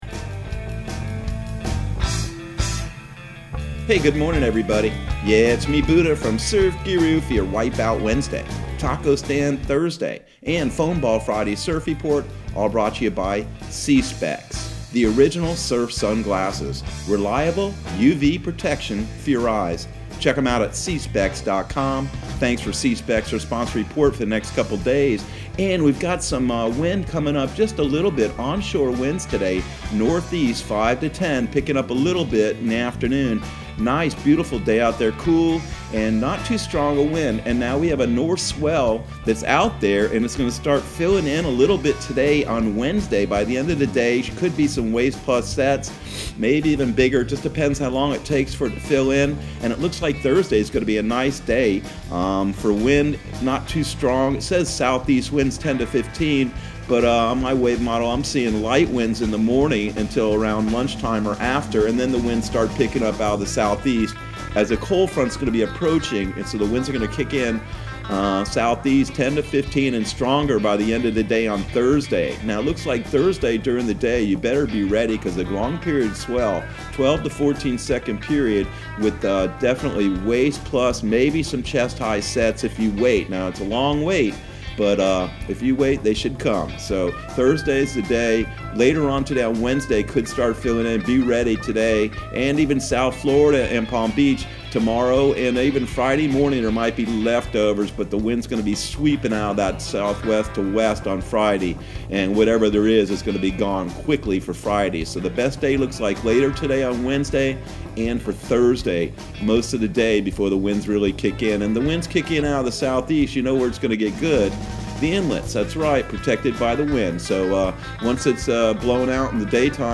Surf Guru Surf Report and Forecast 01/06/2021 Audio surf report and surf forecast on January 06 for Central Florida and the Southeast.